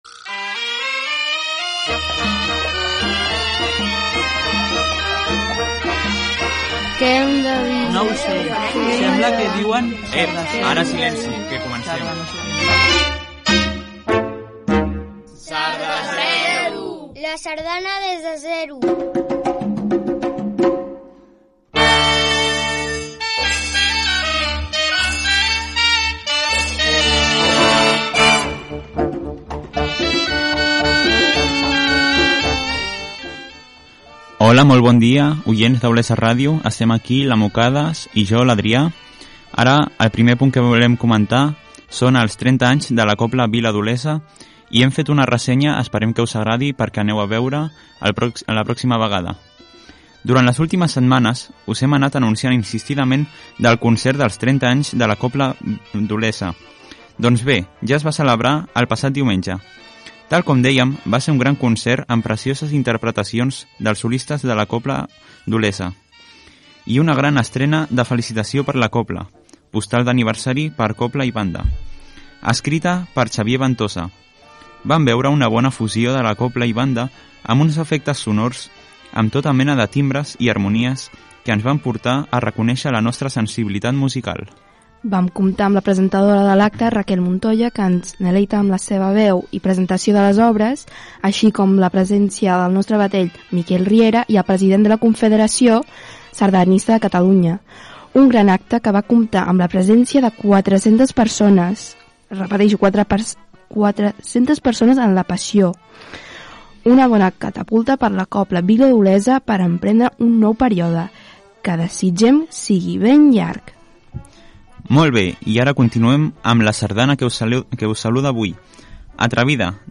Careta del programa sardanista. Salutació i informació dels 30 anys de la Cobla Vila d'Olesa i sardana.
Musical